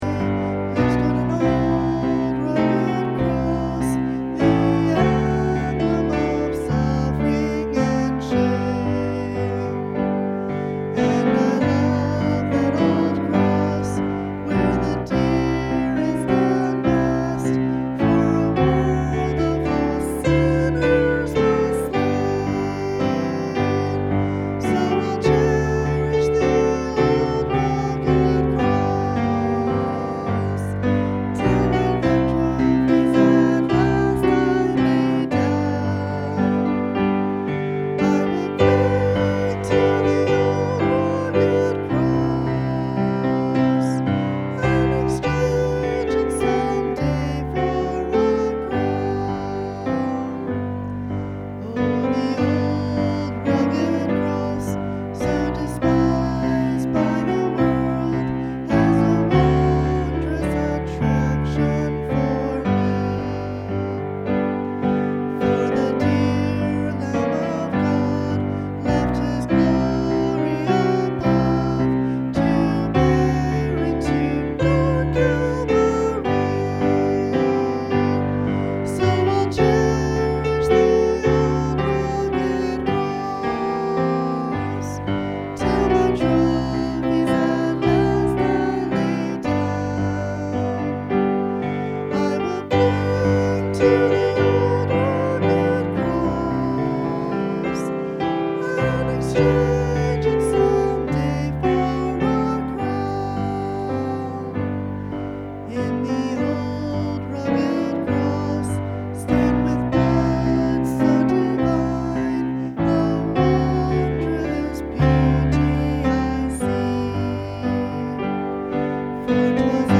“The Great Earthquake!” Matthew 27 Good Friday Service